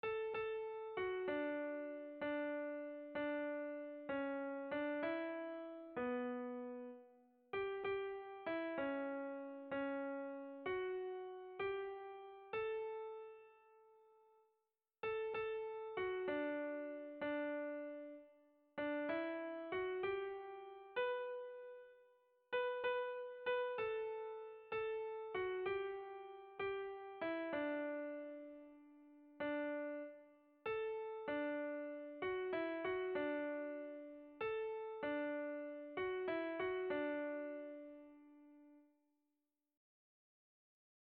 Kontakizunezkoa
Lauko handia (hg) / Bi puntuko handia (ip)
A1A2